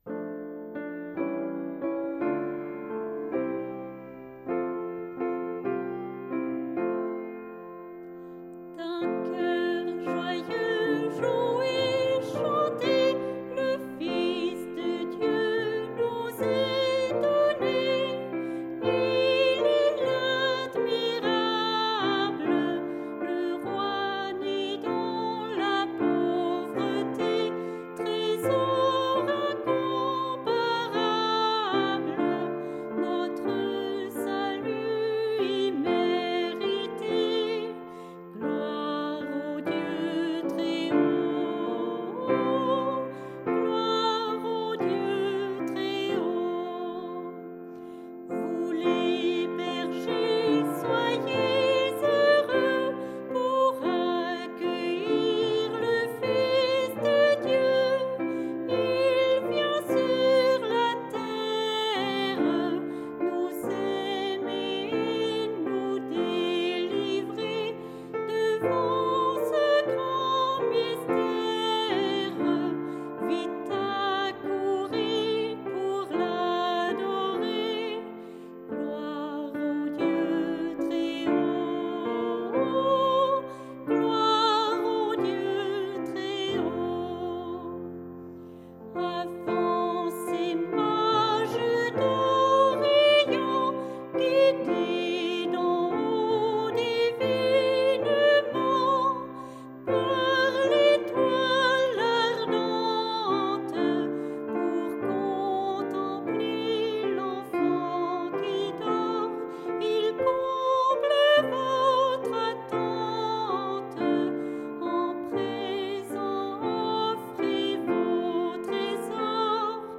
D’un coeur joyeux, jouez, chantez ! – Piano